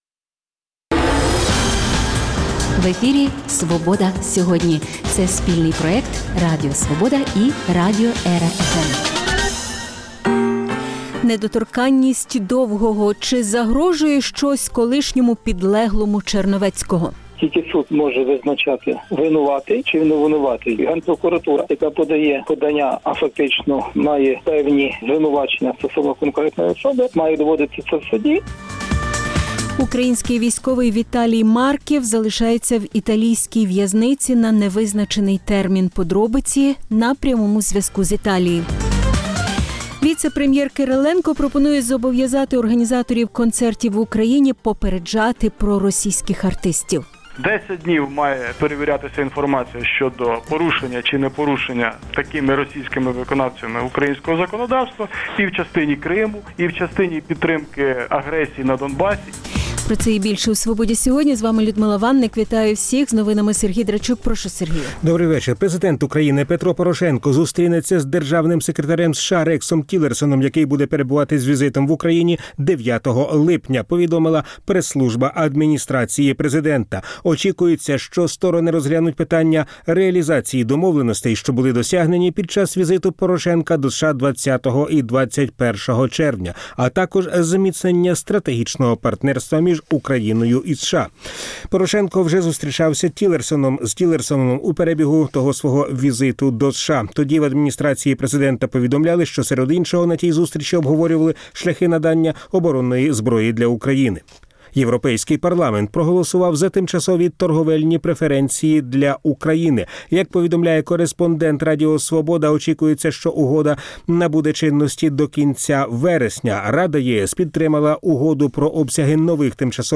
Подробиці – на прямому зв’язку з Італії. Віце-прем’єр Кириленко пропонує зобов’язати організаторів концертів в Україні попереджати про російських артистів.